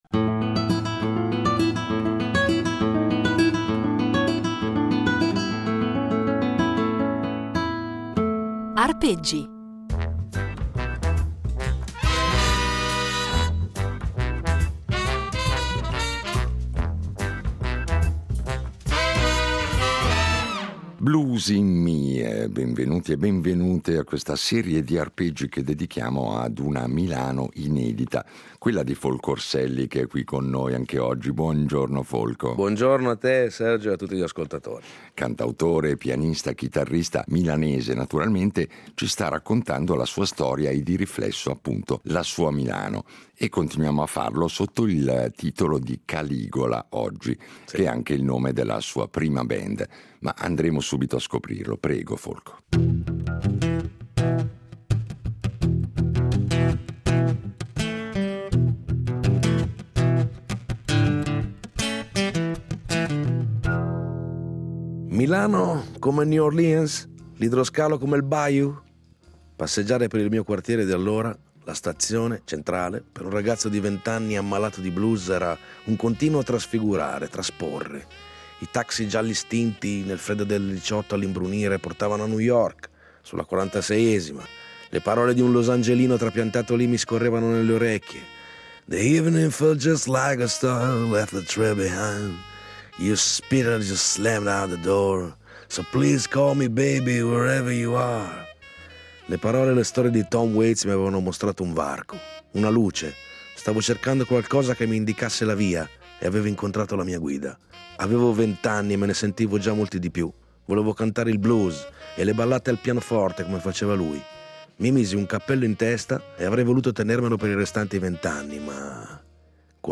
Un itinerario impreziosito, in ogni puntata, da un brano eseguito solo per noi, ai nostri microfoni.